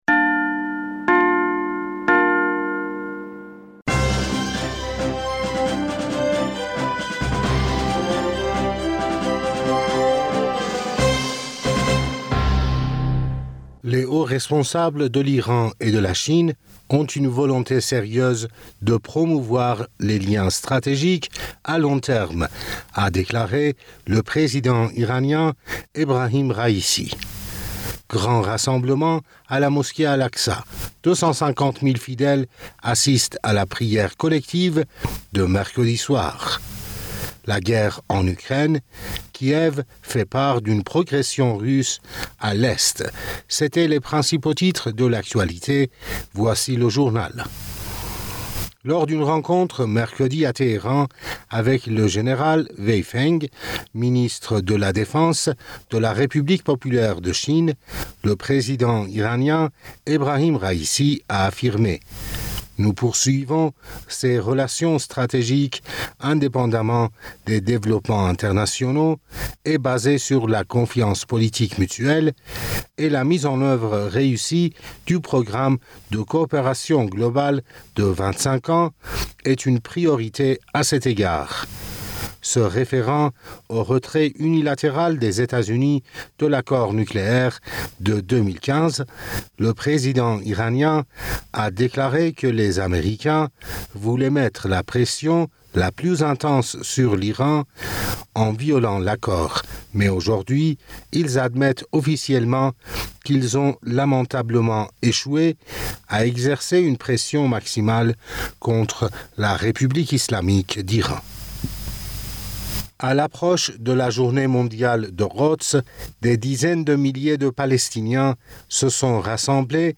Bulletin d'information Du 28 Avril 2022